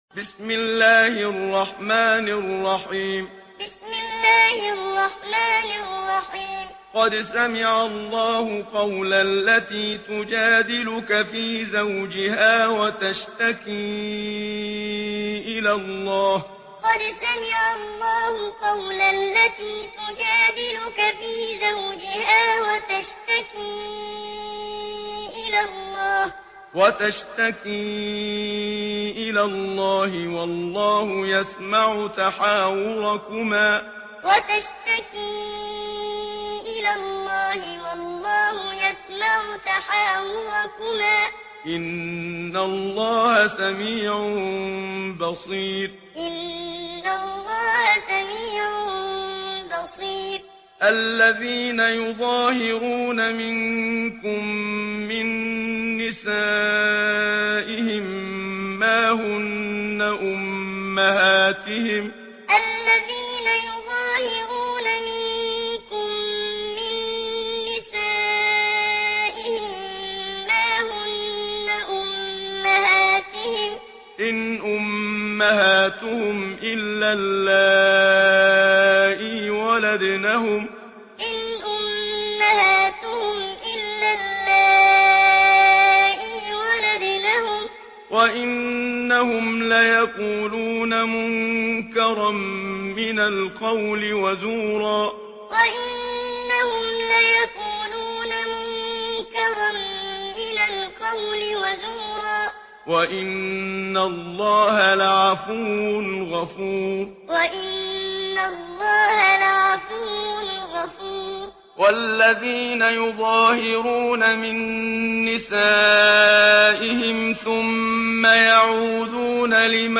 Surah Mücadele MP3 in the Voice of Muhammad Siddiq Minshawi Muallim in Hafs Narration
Listen and download the full recitation in MP3 format via direct and fast links in multiple qualities to your mobile phone.